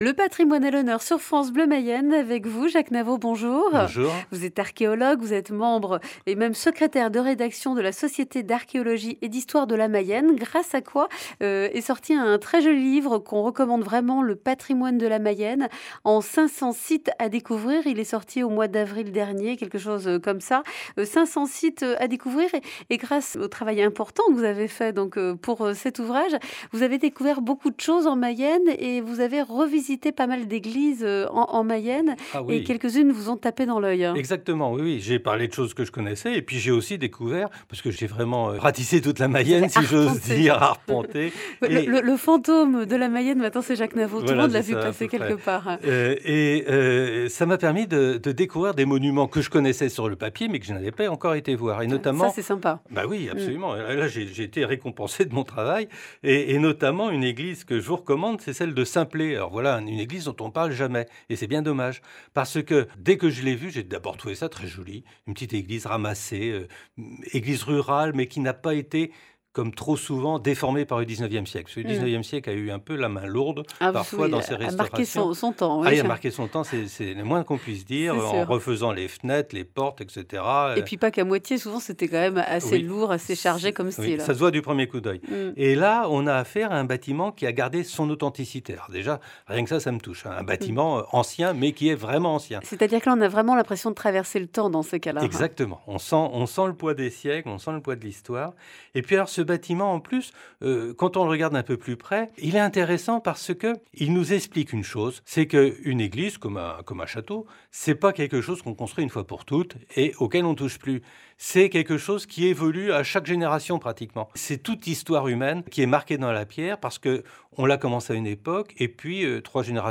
La S.AH.M. sur Radio Bleu Mayenne